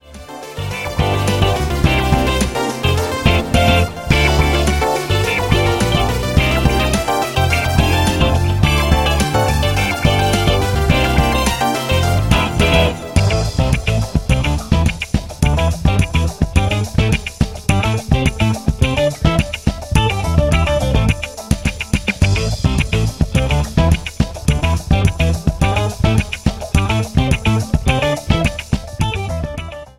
FUNKY  (03.00)